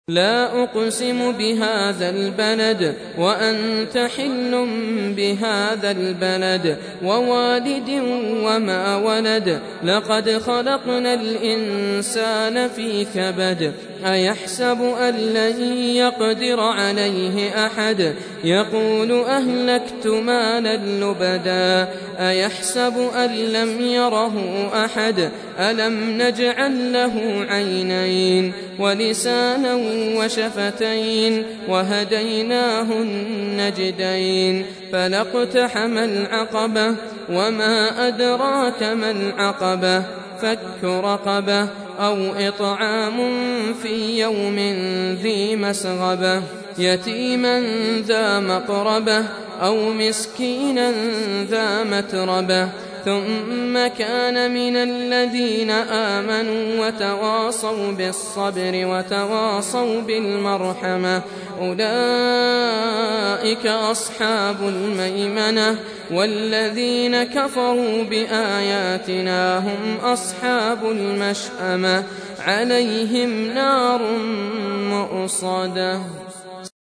Surah Repeating تكرار السورة Download Surah حمّل السورة Reciting Murattalah Audio for 90. Surah Al-Balad سورة البلد N.B *Surah Includes Al-Basmalah Reciters Sequents تتابع التلاوات Reciters Repeats تكرار التلاوات